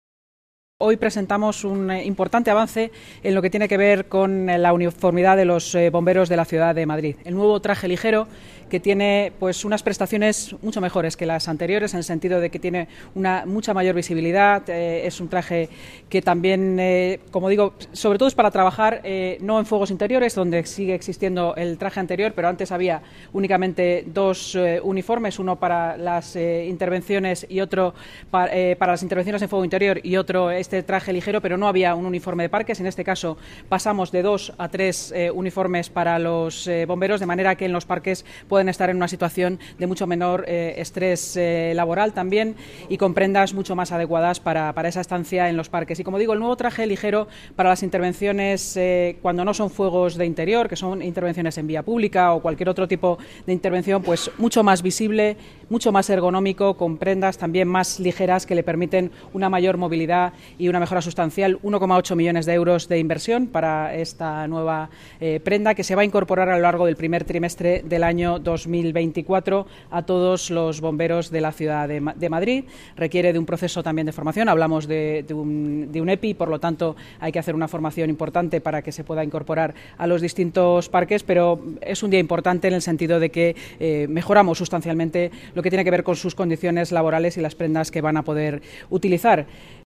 Nueva ventana:Declaraciones de la vicealcaldesa y delegada de Seguridad y Emergencias, Inma Sanz